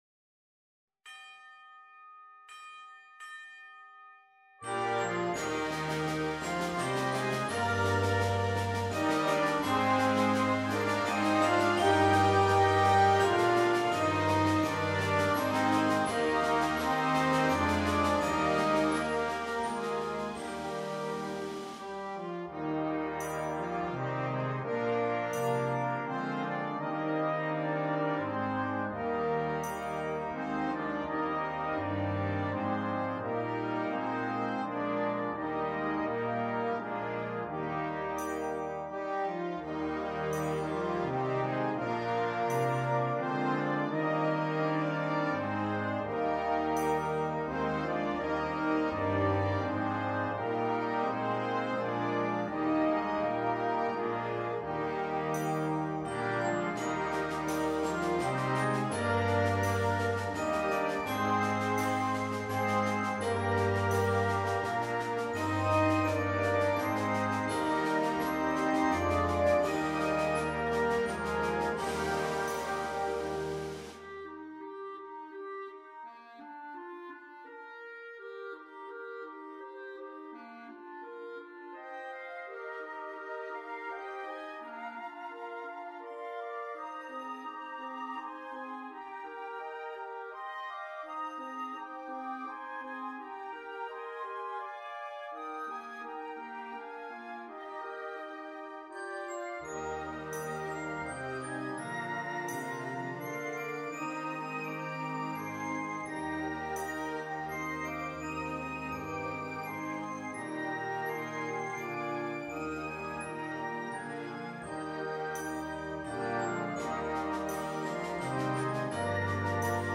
carol arrangement